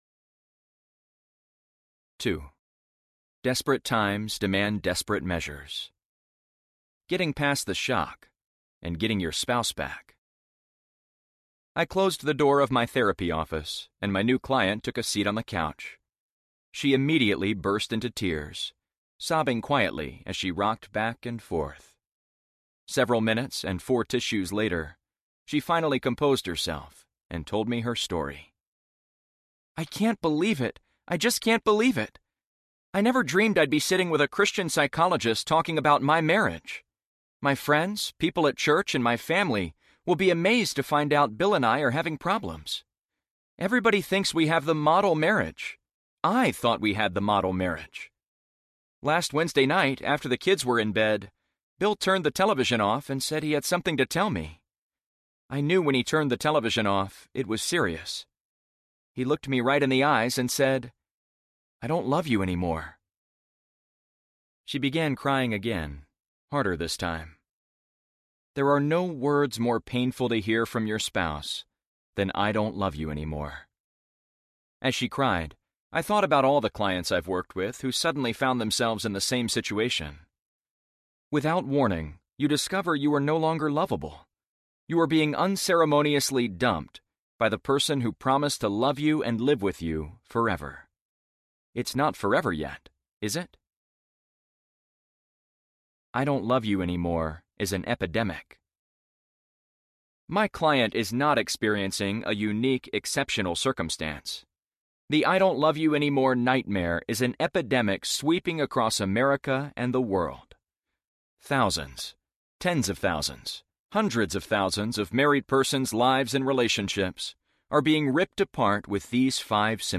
What to Do When He Says, I Don’t Love You Anymore Audiobook